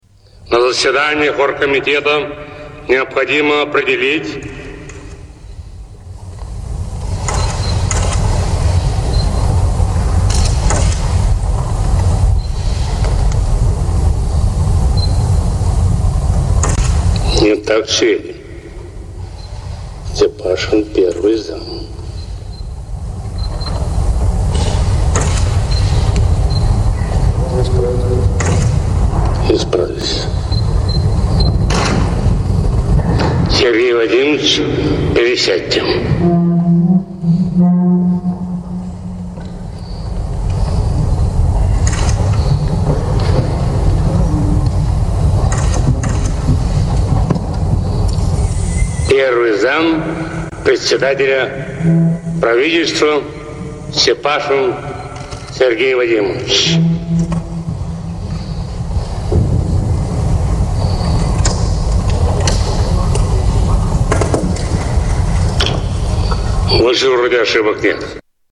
Boris Yeltsin speech